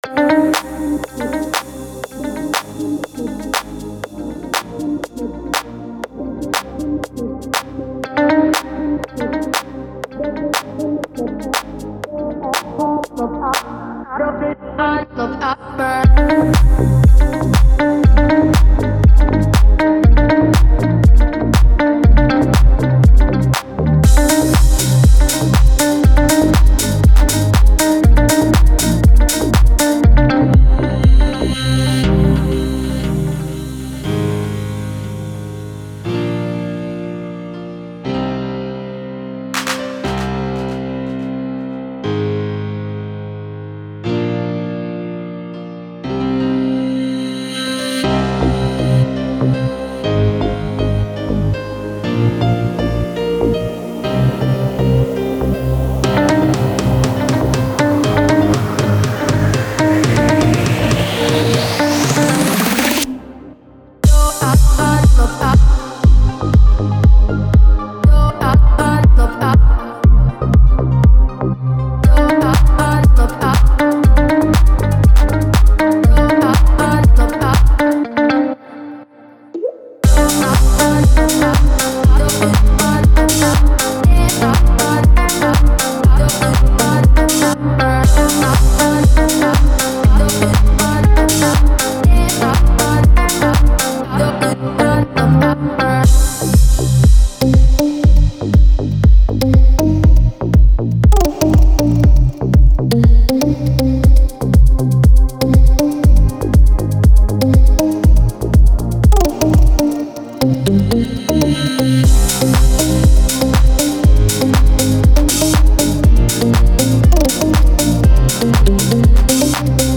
Стиль: Deep House